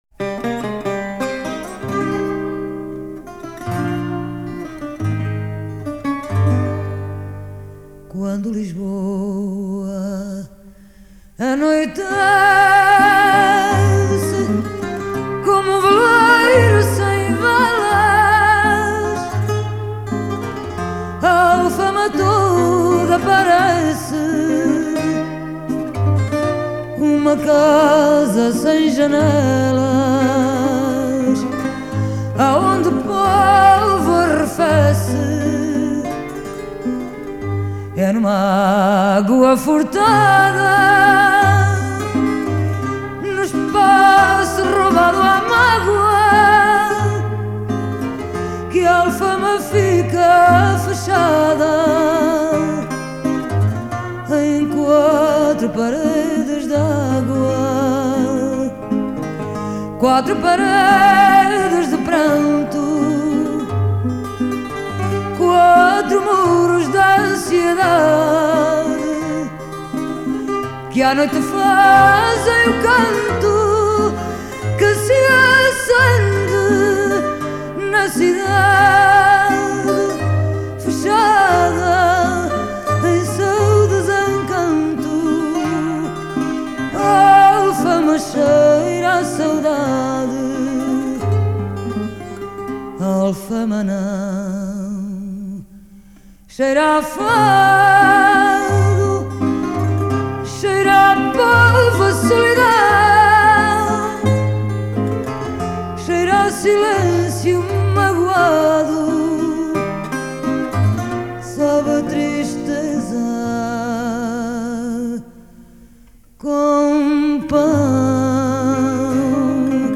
Genre: Fado